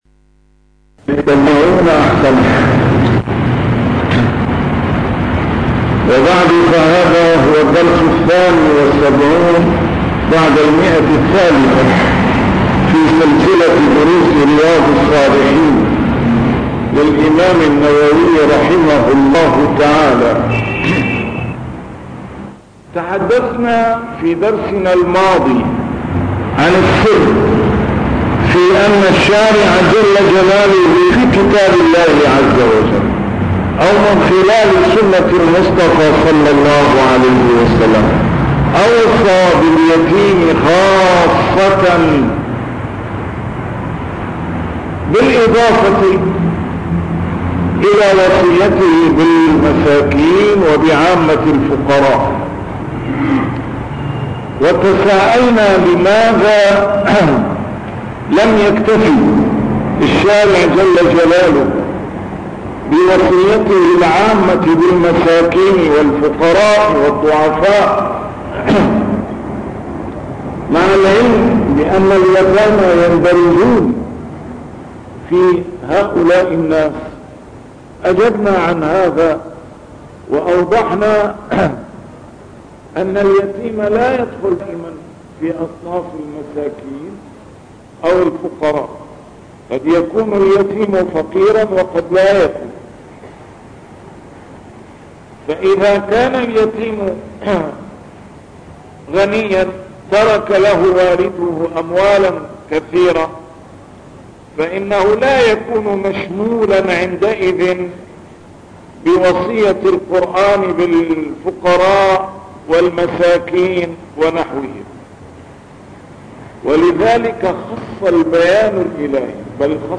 A MARTYR SCHOLAR: IMAM MUHAMMAD SAEED RAMADAN AL-BOUTI - الدروس العلمية - شرح كتاب رياض الصالحين - 372- شرح رياض الصالحين: ملاطفة اليتيم والبنات